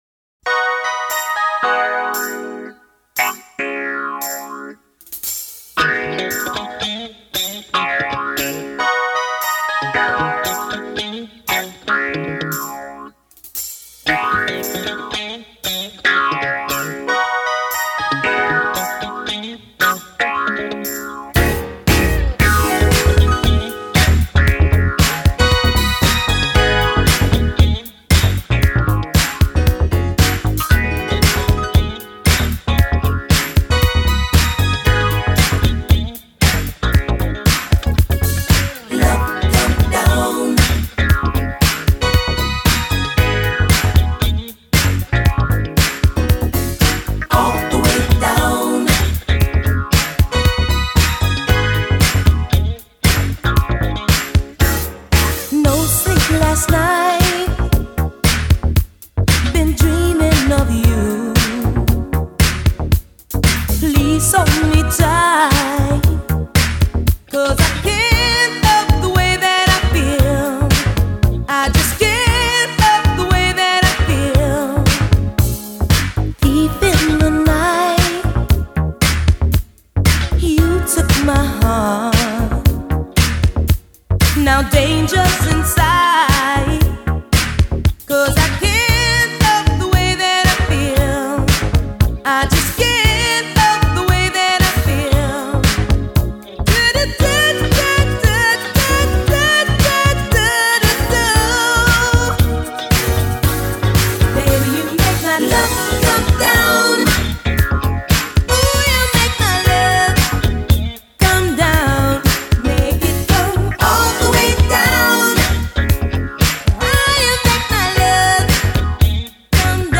disco hit